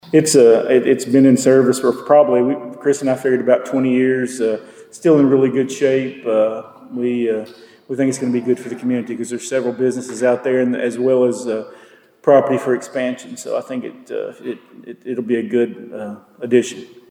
The Princeton City Council received an update on a proposed street acceptance request and approved a one-year extension for a burial site preparation contract at Monday night’s meeting.